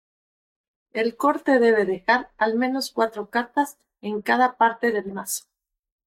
Pronounced as (IPA) /ˈmenos/